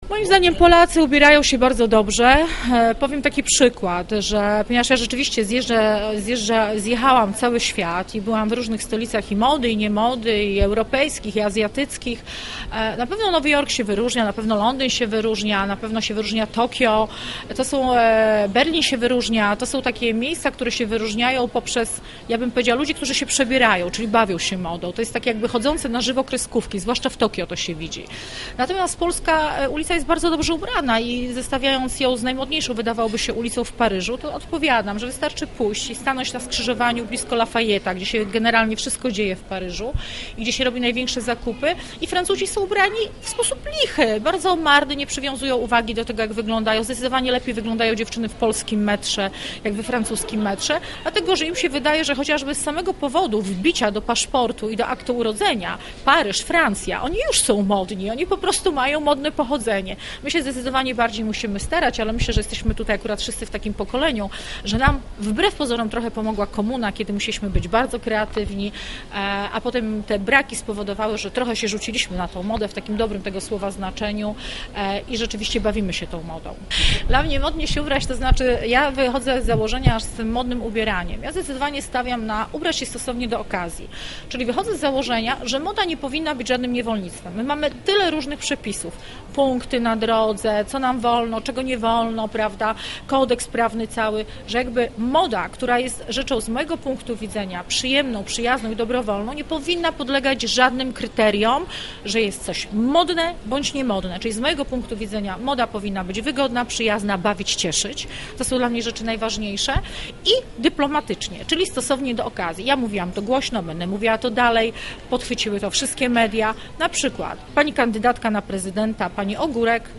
Podczas spotkania z dziennikarzami znana projektantka mówiła głównie o obowiązujących dziś trendach w modzie, oceniła gusta Polaków, doradziła, jak modnie się ubrać.
Ewa Minge w lubińskiej galerii: